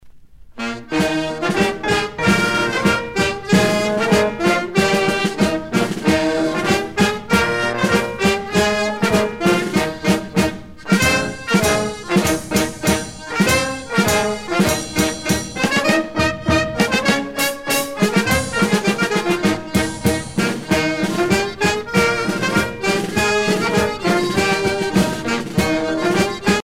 danse : polka
Pièce musicale éditée